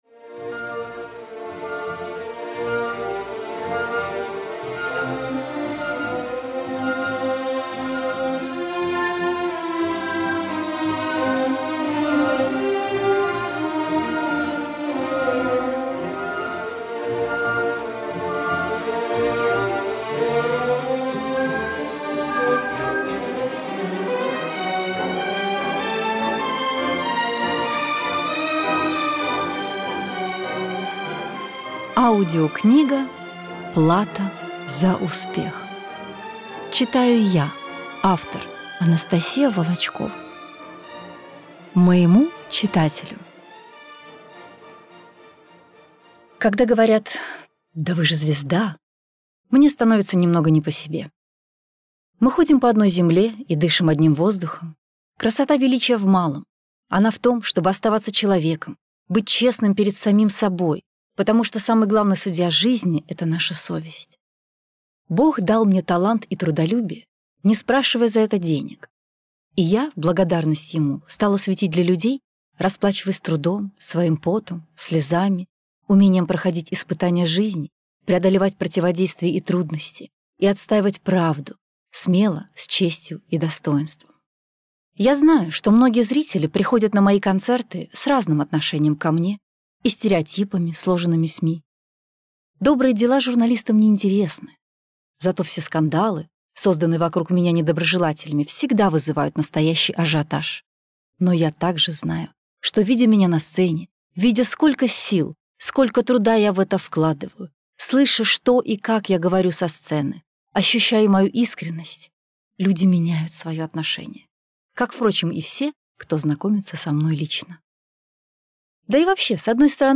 Аудиокнига Плата за успех. Откровенная автобиография | Библиотека аудиокниг